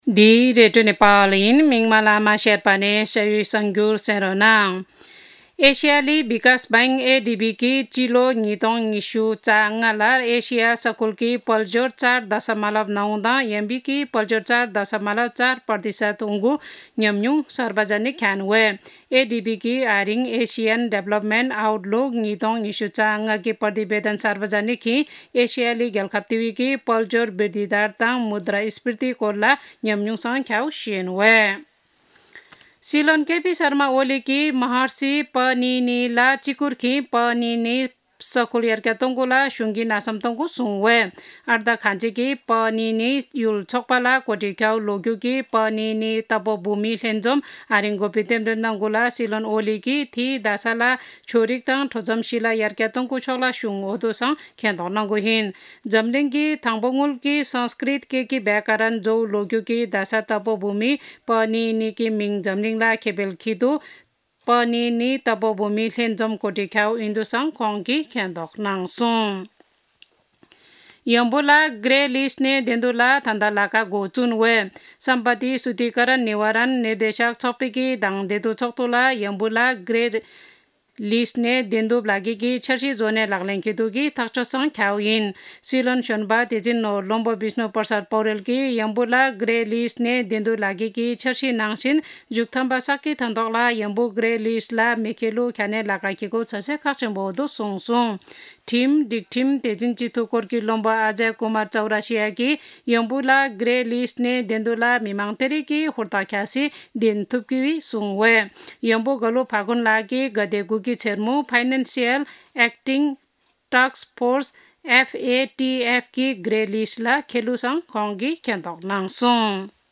शेर्पा भाषाको समाचार : २७ चैत , २०८१
shearpa-news-1-1.mp3